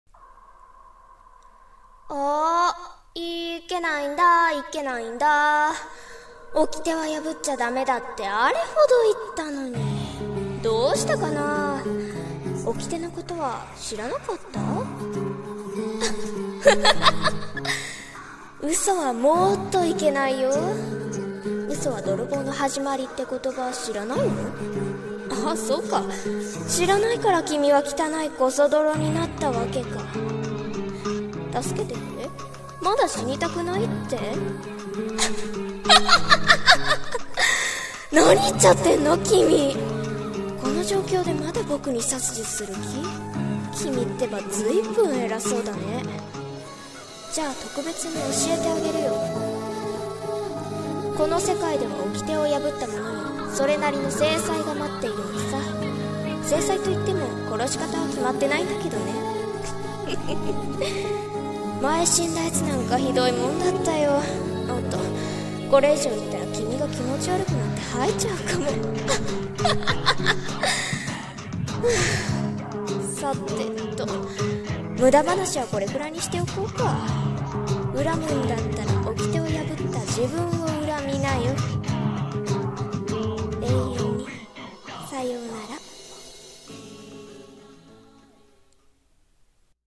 【1人声劇】